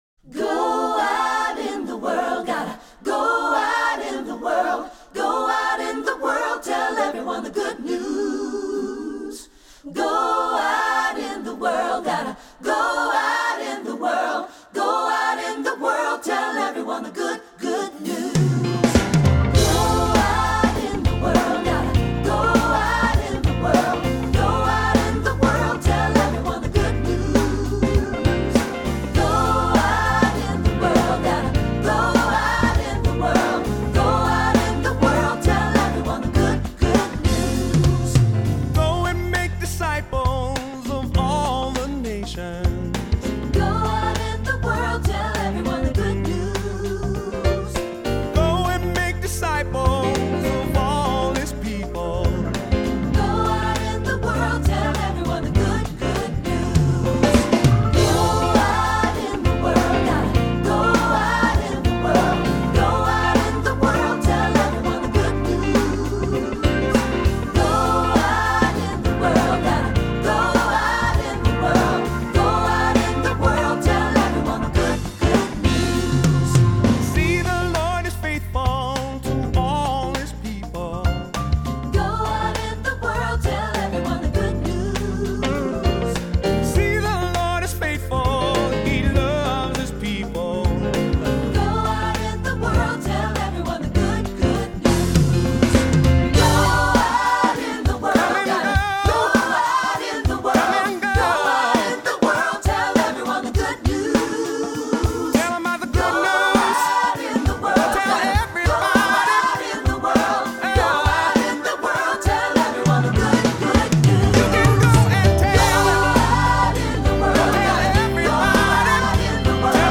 Voicing: SATB; Solo; Cantor; Assembly